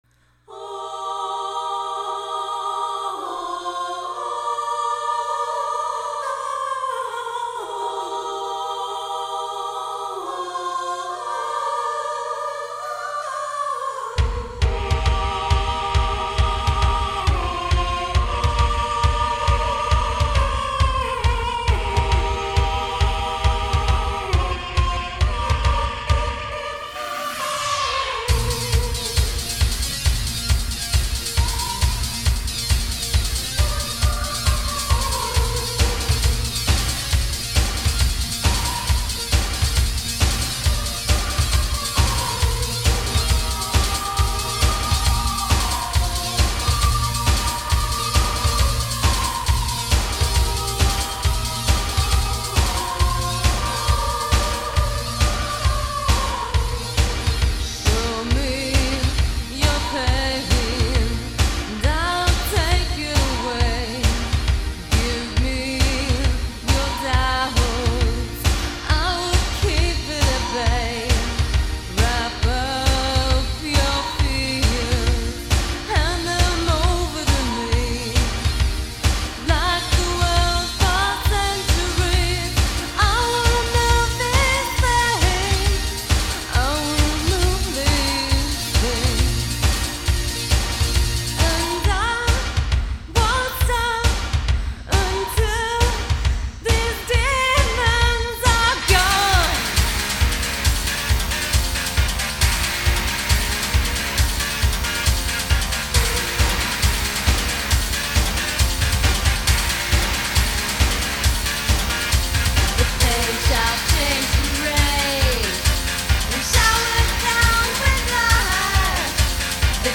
Electronic / Experimental
Club Dance
Techno / Industrial
Synth Pop
Avante Garde